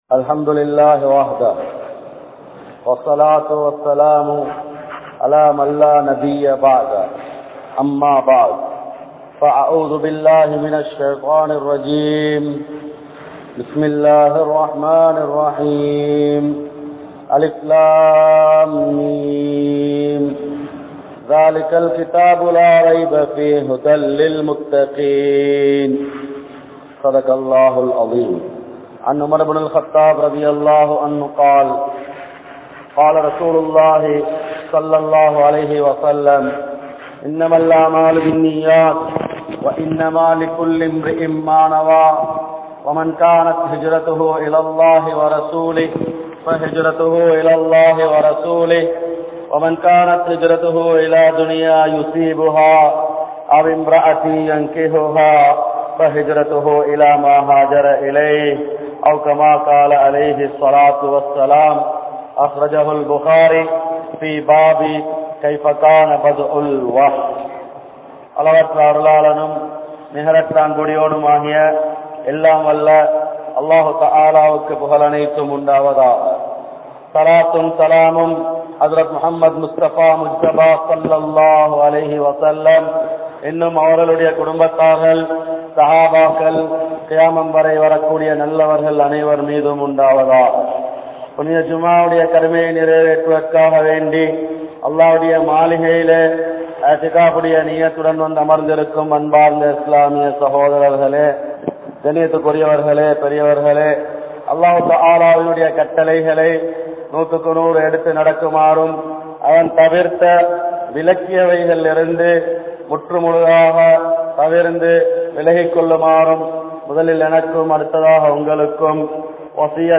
Nam Munnoarhalum Naamum (நம் முன்னோர்களும் நாமும்) | Audio Bayans | All Ceylon Muslim Youth Community | Addalaichenai
Kaluthura, Hilru (Oorukkul Palli) Jumua Masjidh